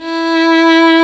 Index of /90_sSampleCDs/Roland - String Master Series/STR_Violin 2&3vb/STR_Vln3 % + dyn
STR VLN3 E 3.wav